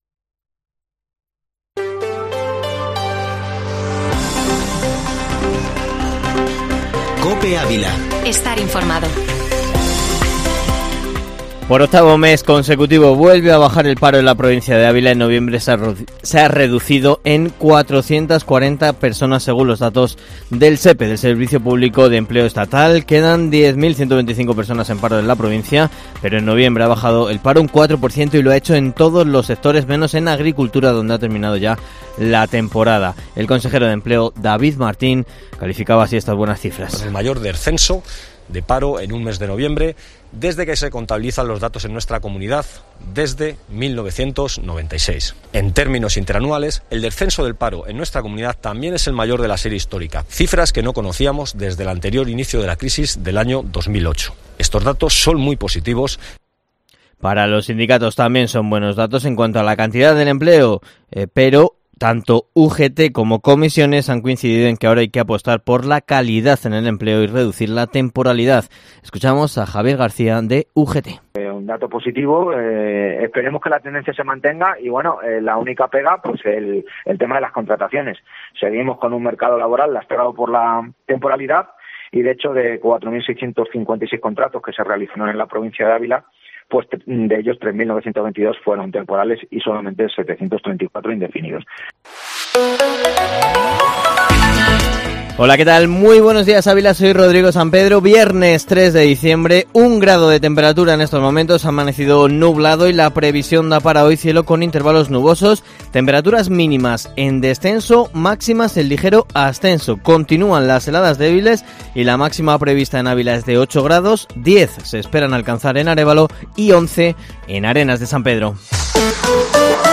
Informativo Matinal Herrera en COPE Ávila -3-dic